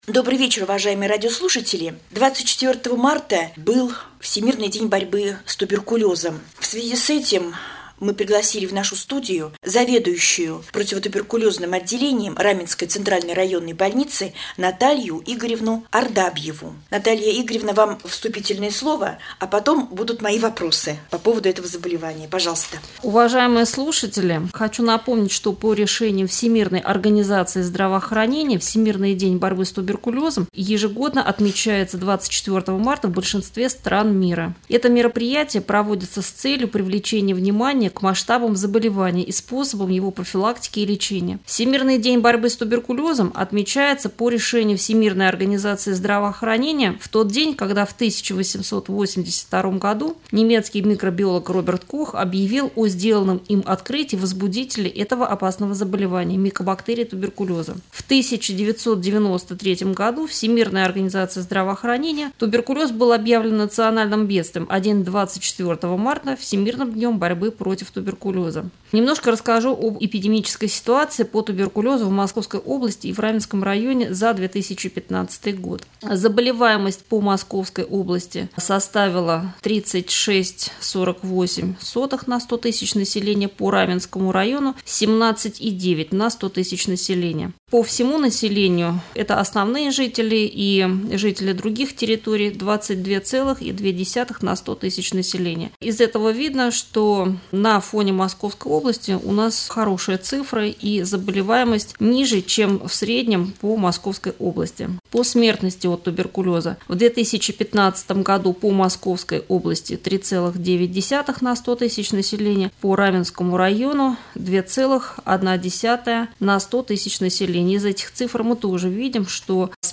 В студии Раменского радио